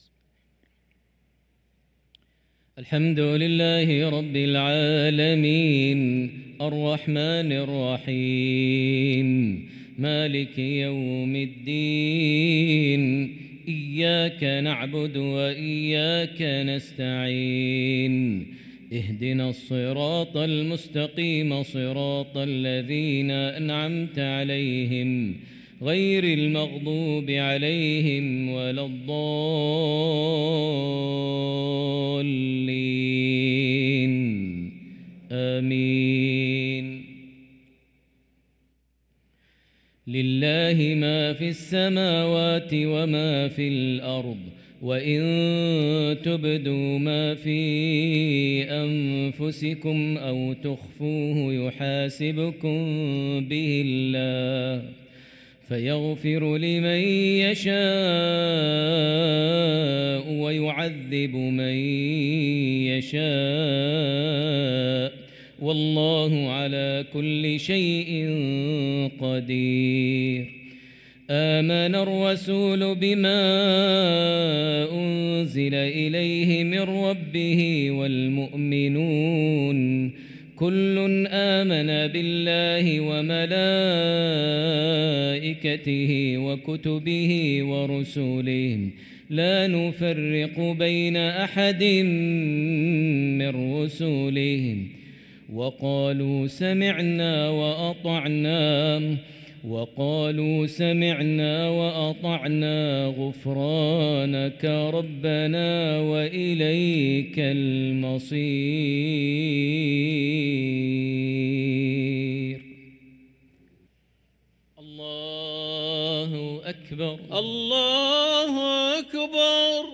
صلاة المغرب للقارئ ماهر المعيقلي 5 ربيع الآخر 1445 هـ